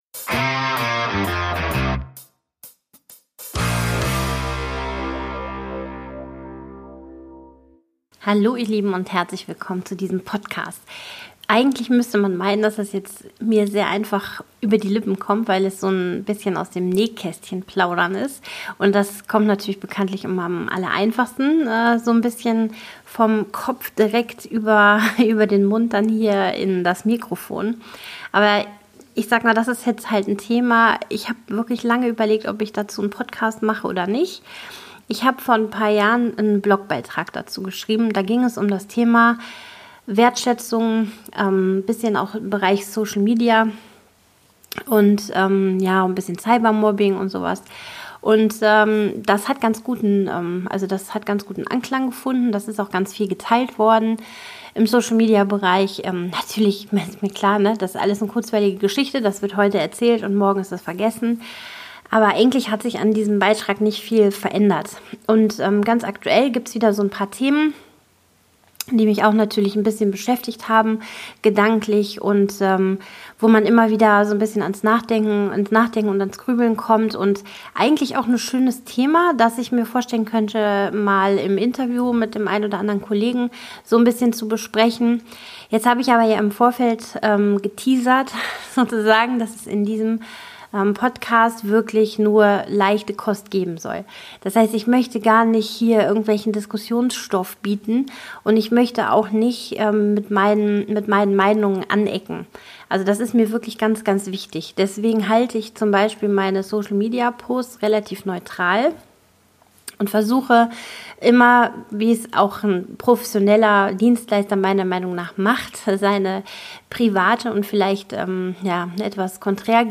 Heute halte ich ein Pläuschchen mit mir selbst und hoffe Ihr hört zu. Es geht um die Themen Segen und Fluch der Sozialen Netzwerke, Wertschätzung aus zwei Perspektiven und den großen Wunsch nach Toleranz.